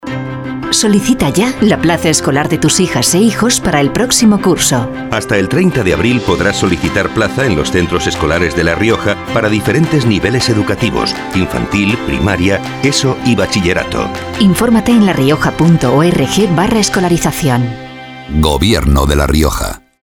Elementos de campaña Cuñas radiofónica Cuña de 20".